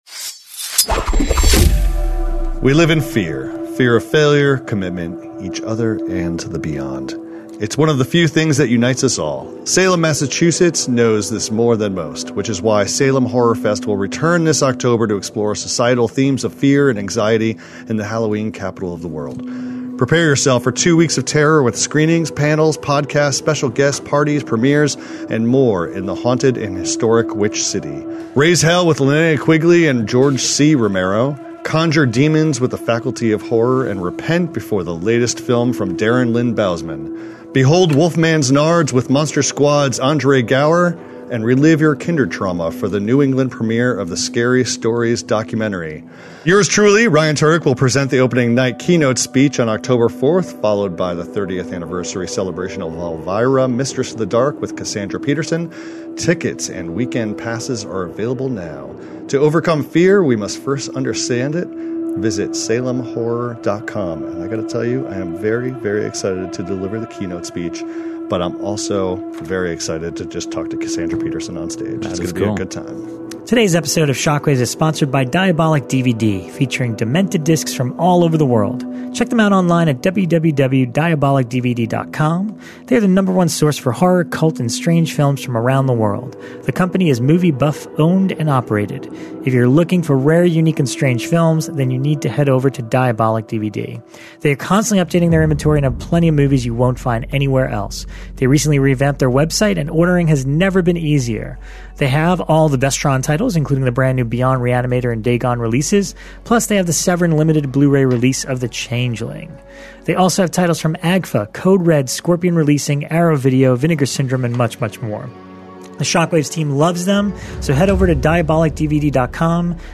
The Shock Waves hosts are back in the studio to discuss the latest horrors, including a rare FRIDAY THE 13TH PART 3 screening in 3D, Hulu's CASTLE ROCK, DEAD NIGHT, THE WITCH IN THE WINDOW, STUNT ROCK, Scream Factory's RETURN OF THE LIVING DEAD PART 2, and more!